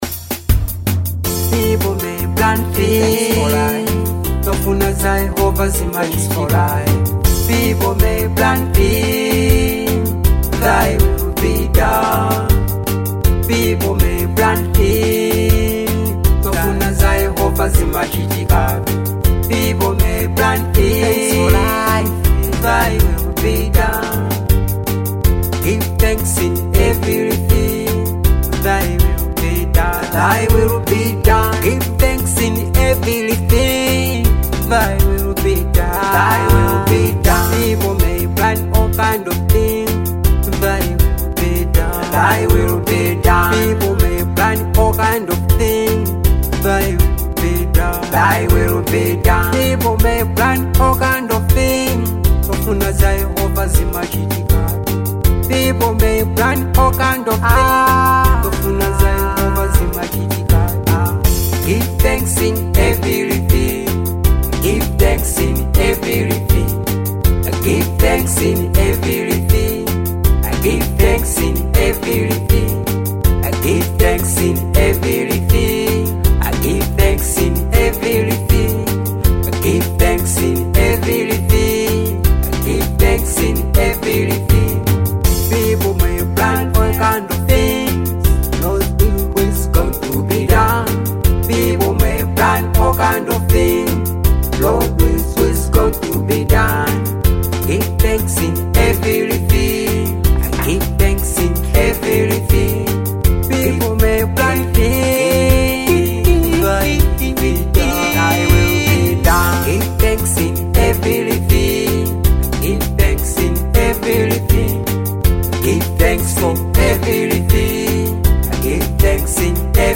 Genre : Afro Beat